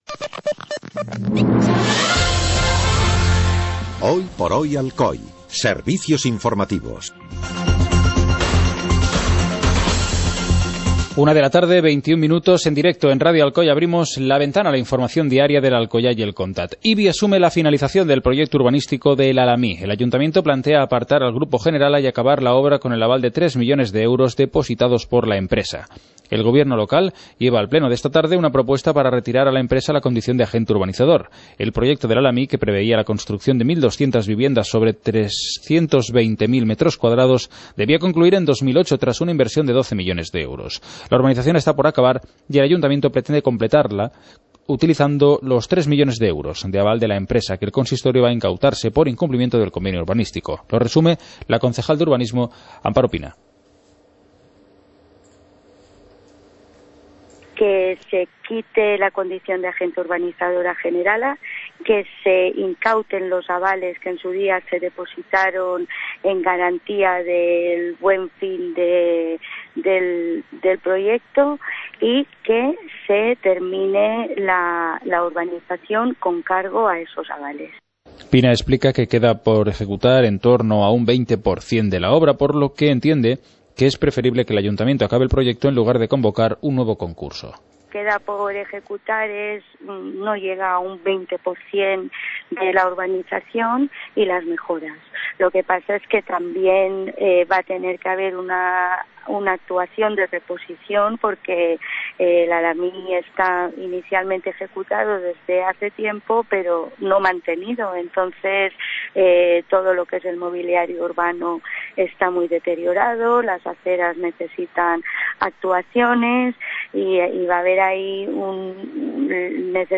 Informativo comarcal - lunes, 06 de octubre de 2014